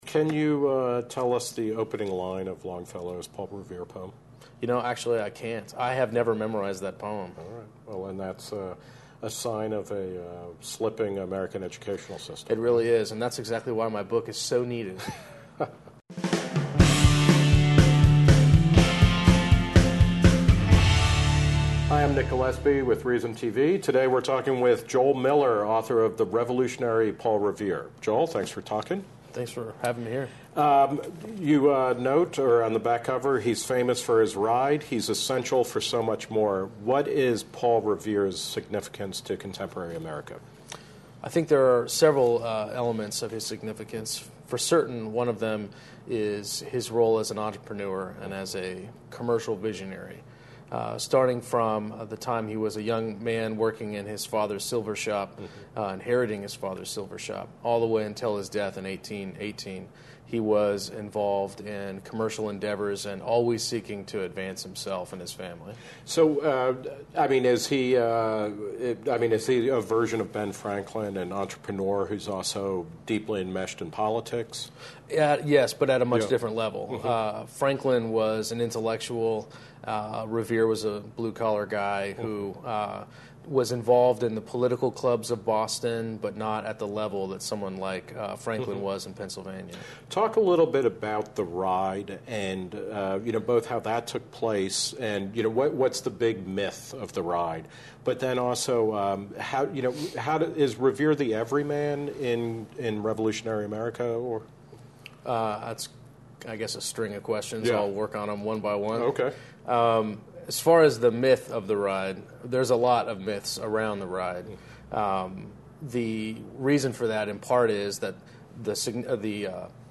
The Reason Interview